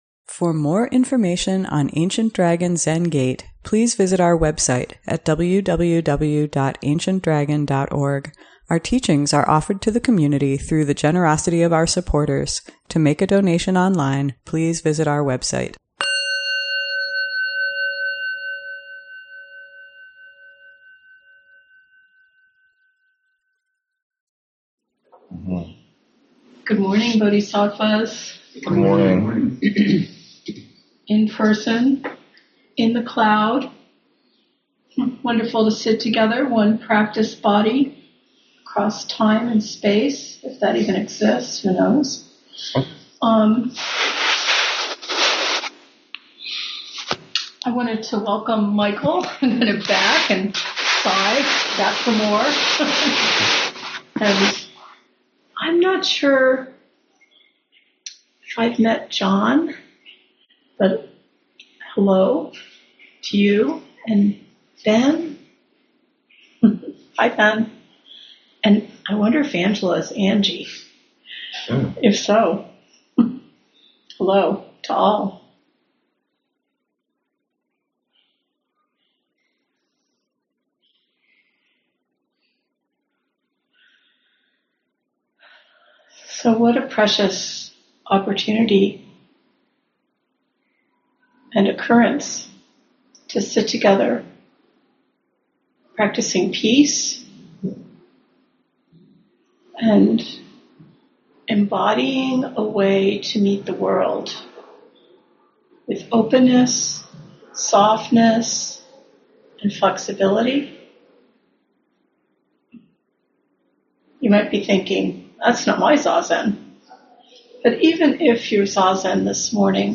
ADZG Sunday Morning Dharma Talk